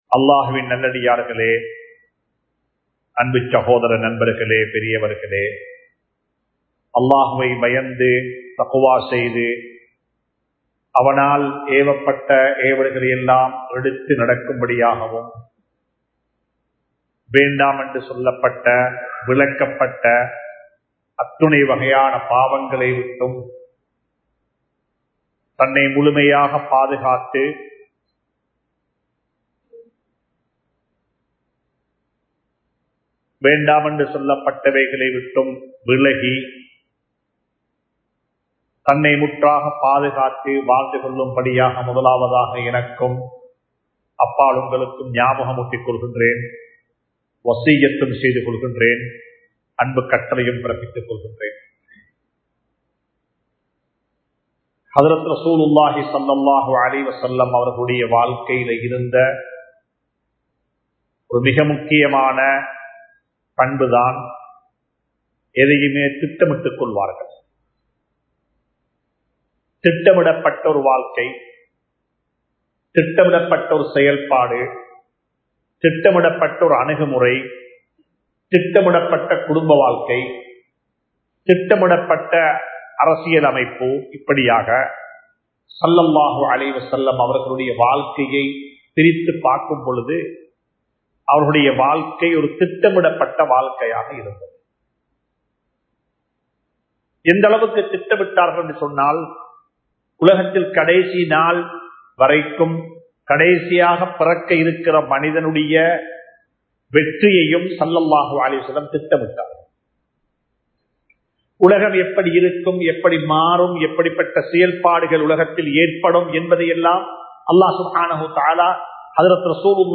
ரமழானும் நாமும் | Audio Bayans | All Ceylon Muslim Youth Community | Addalaichenai
Yasir Arafath Jumua Masjidh